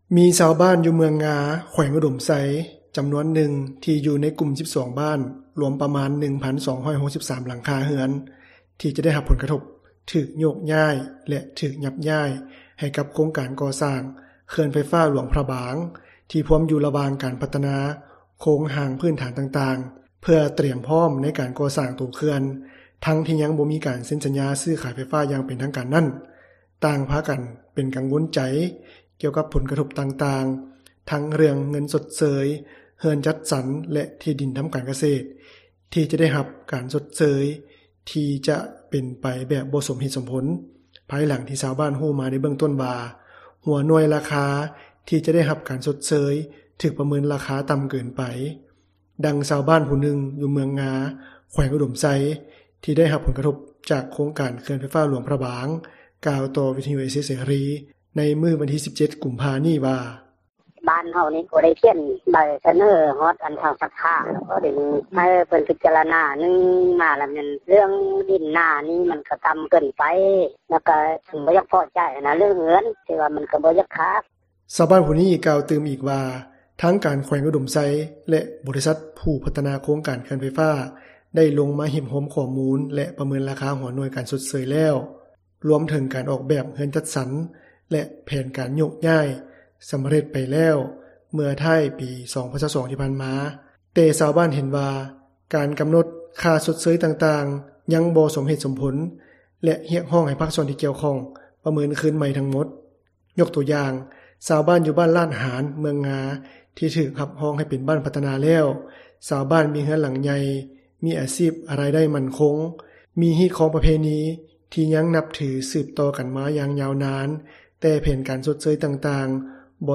ດັ່ງຊາວບ້ານຜູ້ນຶ່ງ ຢູ່ເມືອງງາ ແຂວງອຸດົມໄຊ ທີ່ໄດ້ຮັບຜົລກະທົບ ຈາກໂຄງການເຂື່ອນໄຟຟ້າຫຼວງພຣະບາງ ກ່າວຕໍ່ວິທຍຸເອເຊັຽເສຣີ ໃນມື້ວັນທີ 17 ກຸມພາ ນີ້ວ່າ: